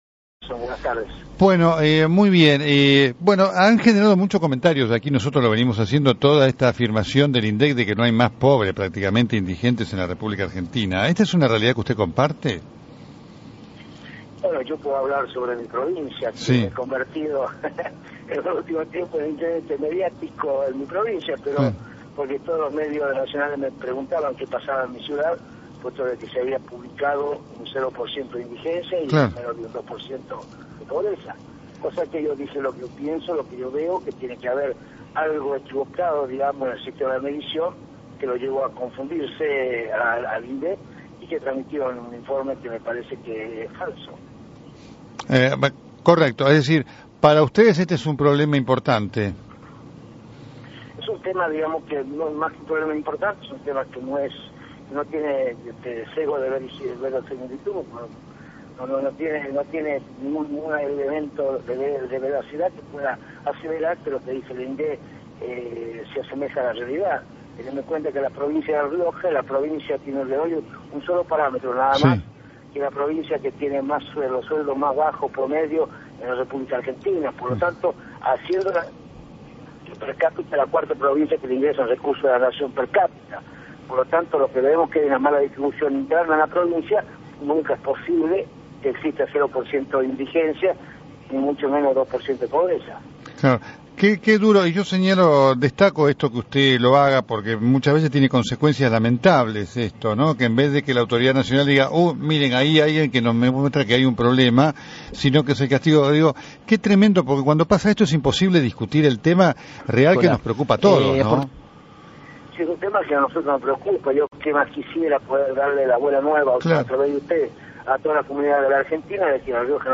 El intendente remarcó que los datos «no tienen sesgo de verosimilitud con la realidad». Quintela habló en el programa Entre líneas que conduce el periodista Nelson Castro en Radio Continental.